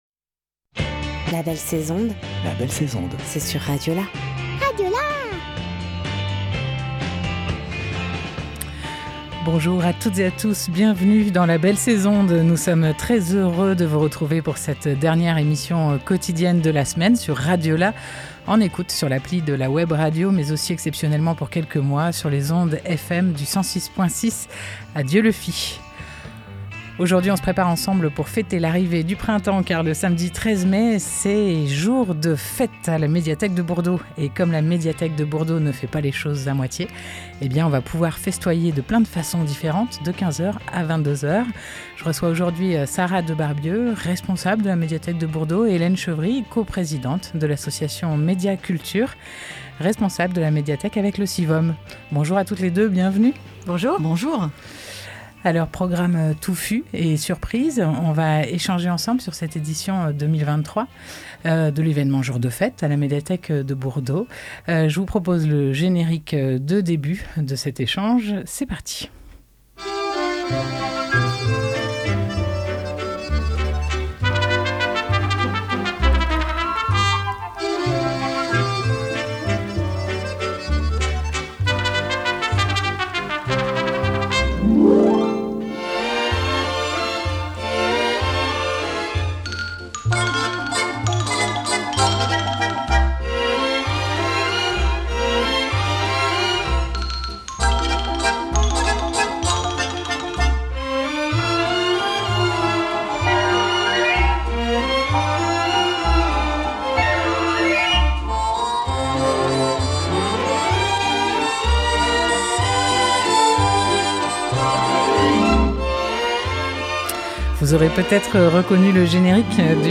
5 mai 2023 17:16 | Interview, la belle sais'onde